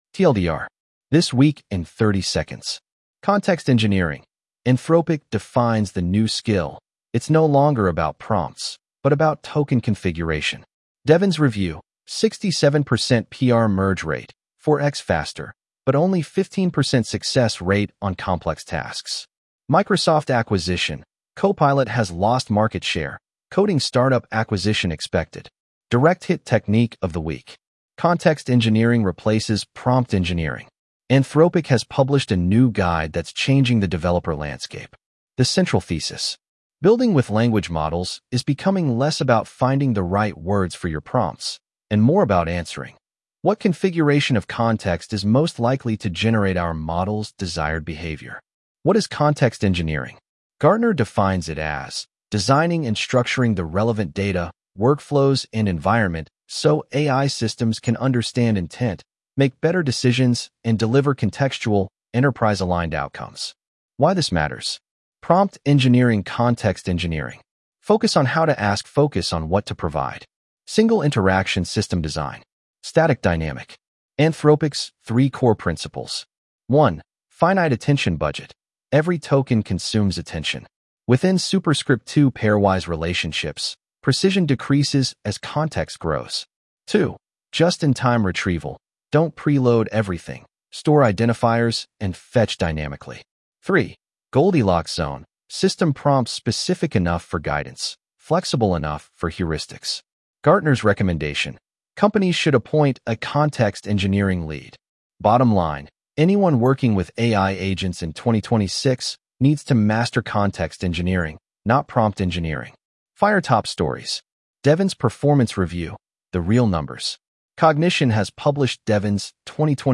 Read aloud with edge-tts (Microsoft Azure Neural Voice: en-US-GuyNeural)